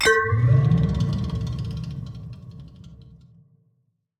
Minecraft Version Minecraft Version snapshot Latest Release | Latest Snapshot snapshot / assets / minecraft / sounds / block / respawn_anchor / charge1.ogg Compare With Compare With Latest Release | Latest Snapshot